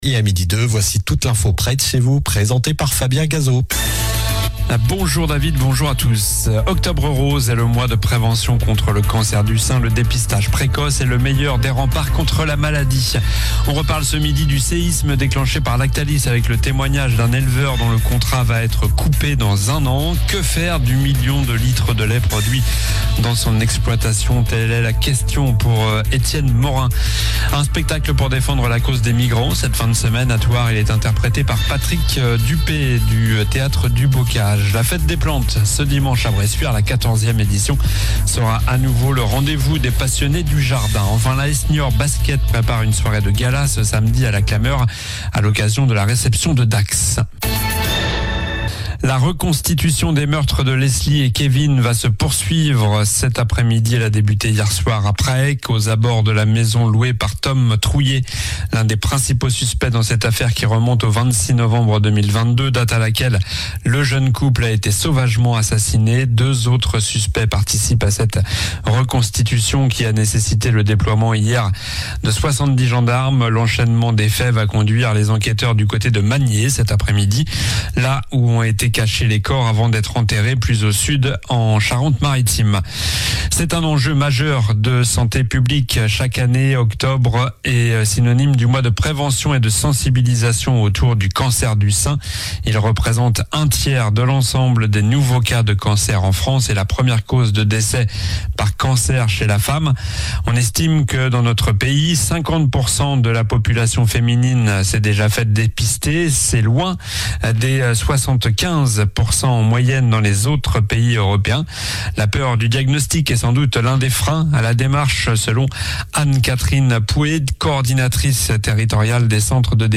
Journal du jeudi 03 octobre (midi)